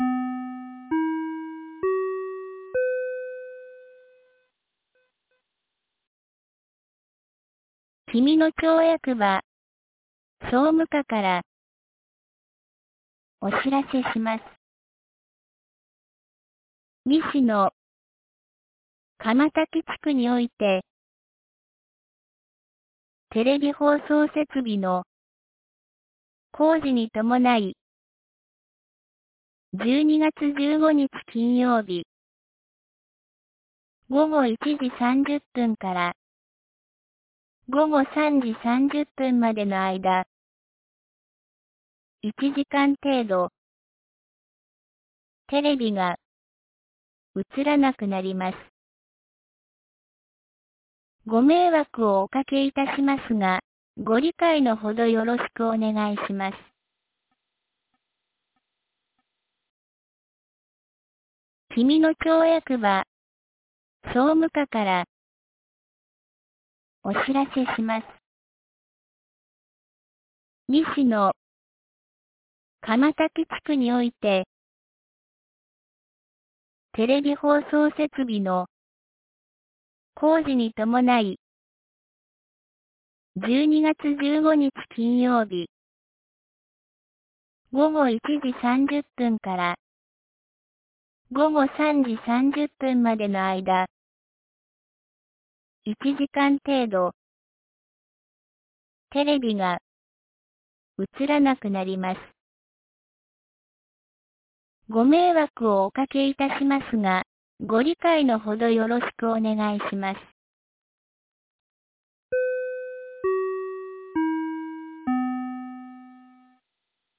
2023年12月14日 12時37分に、紀美野町より志賀野地区へ放送がありました。